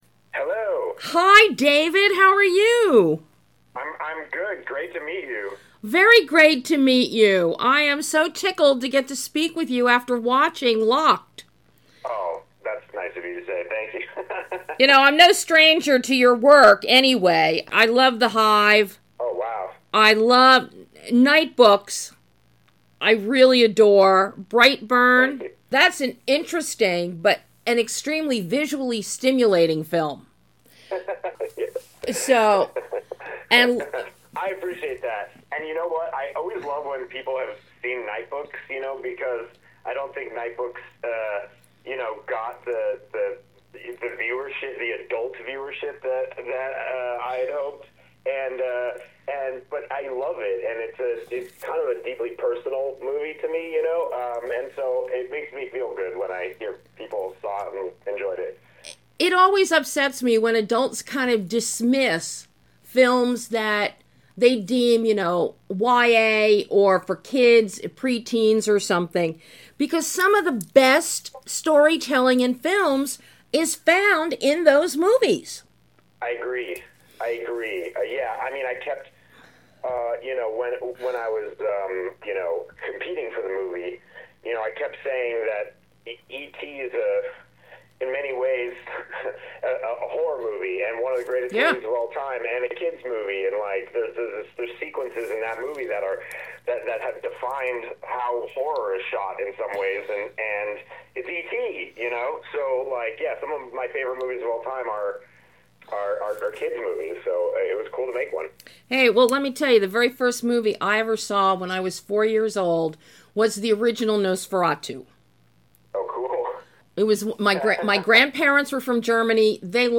LOCKED - Exclusive Interview